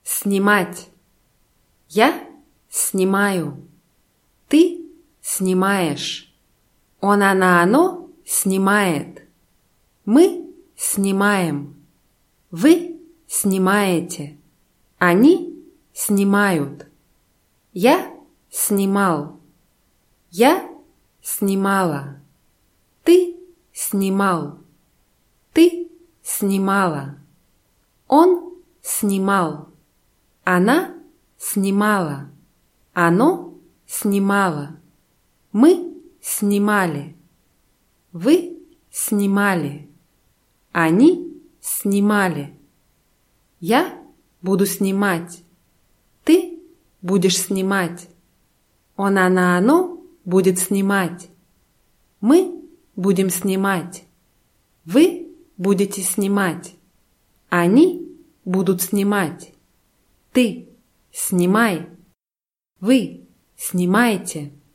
снимать [snʲimátʲ]